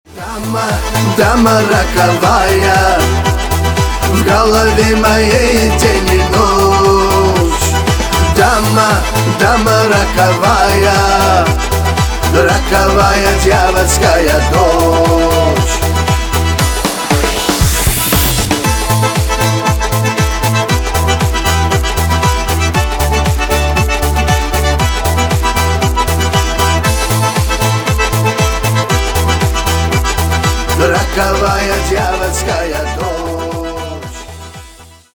восточные на русском на девушку про любовь